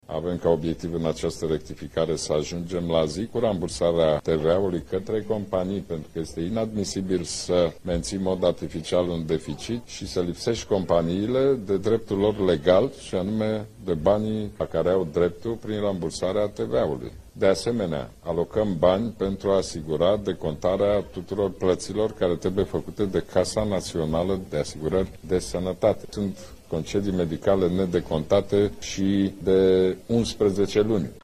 Potrivit premierului Ludovic Orban, mediul de afaceri nu trebuie să mai fie afectat de deciziile politicienilor:
ludovic-orban-rectificare-bugetara.mp3